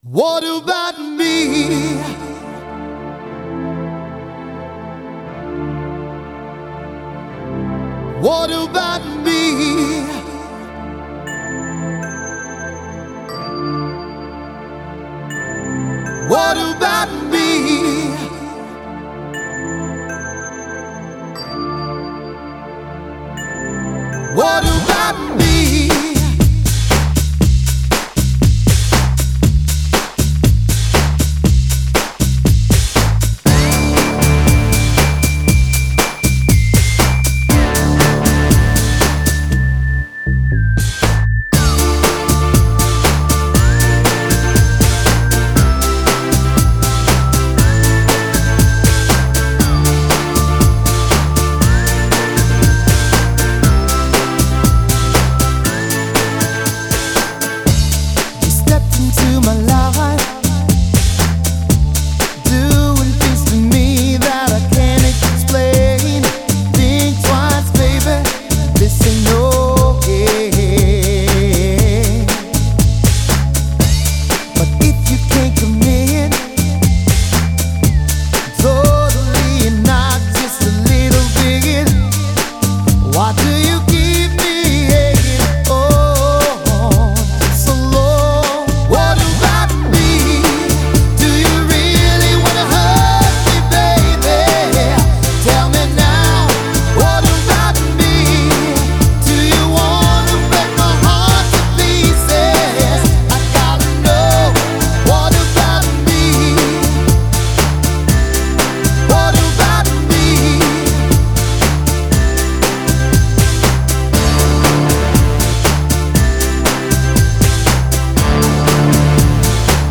Genre: Euro-House.